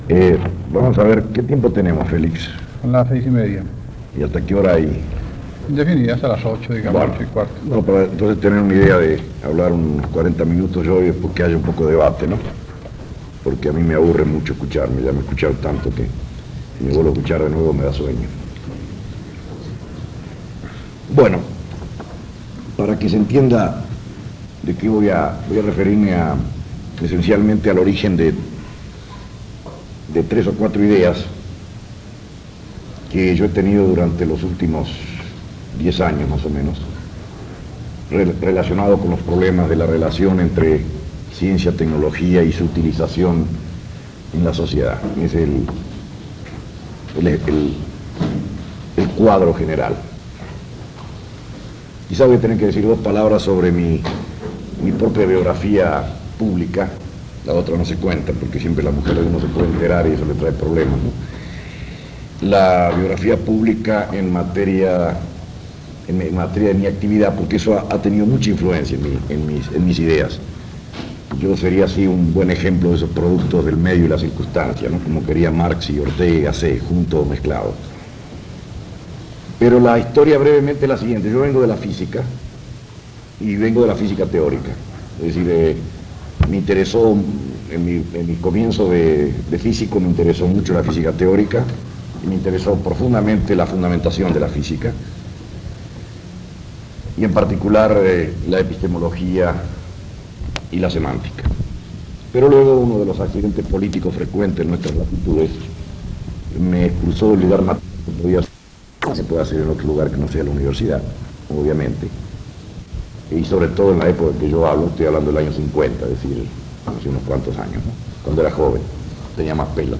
Conferencia en Caracas
Conferencia dictada en el Instituto ISEA. Caracas, Venezuela.